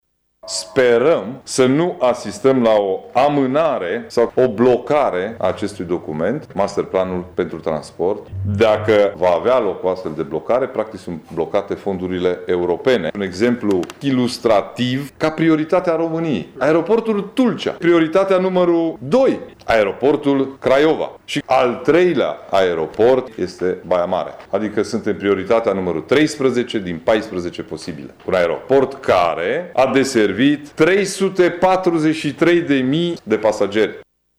Semnalul de alarmă a fost tras ieri, într-o conferinţă de presă, de președintele CJ Mureș, Ciprian Dobre.